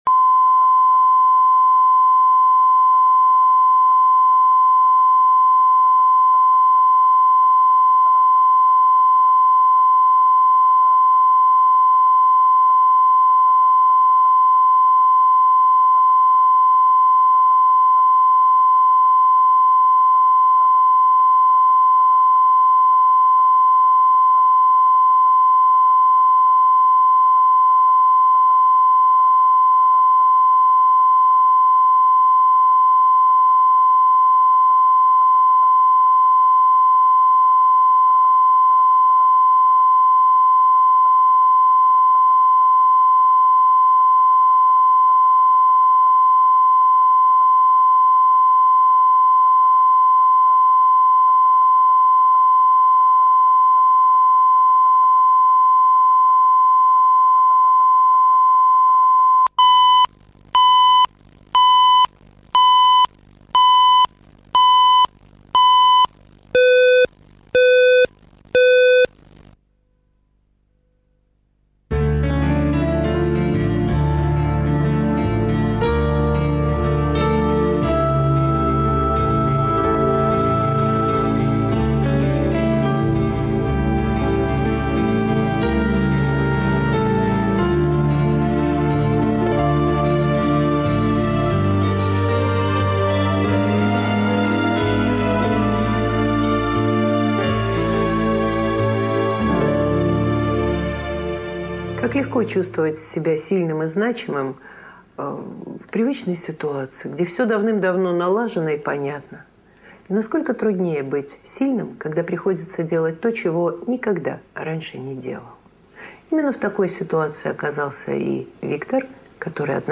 Aудиокнига Морской волк Автор Александр Левин.